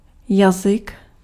Ääntäminen
IPA : /ˈlæŋɡwɪdʒ/